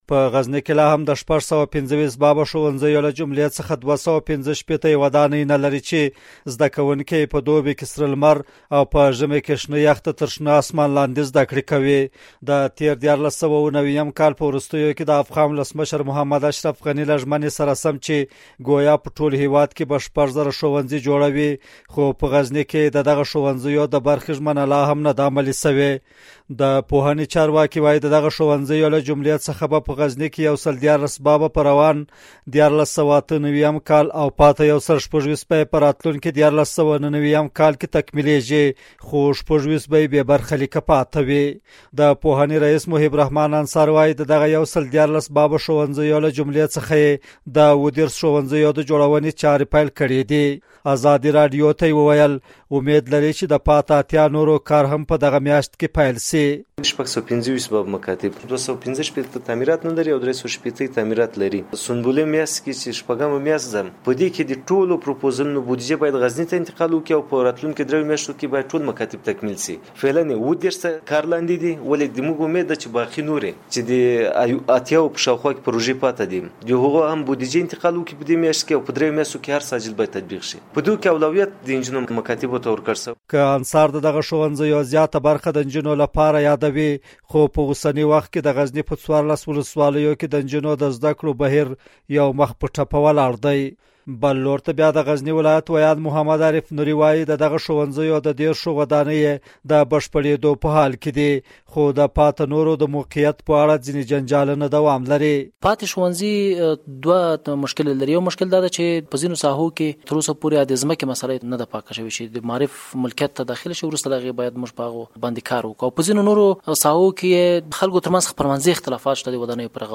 د غزني راپور